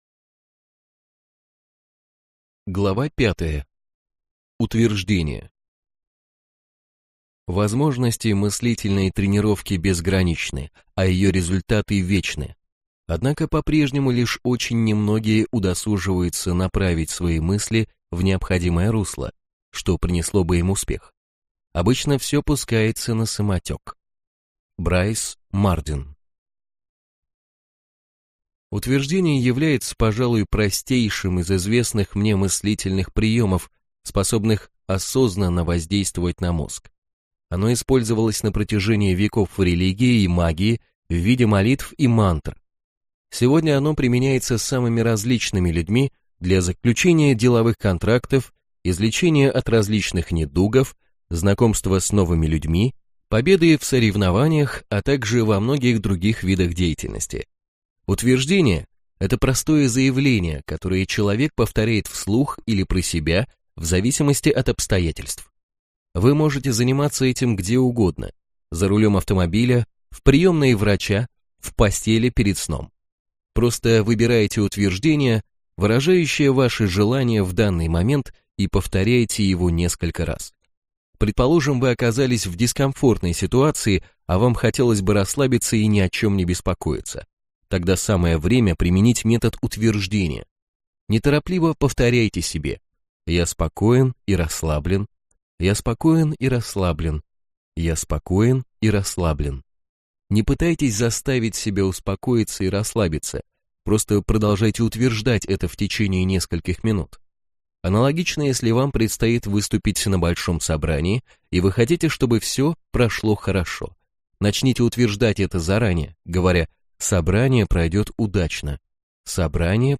Аудиокнига Подсознание может всё!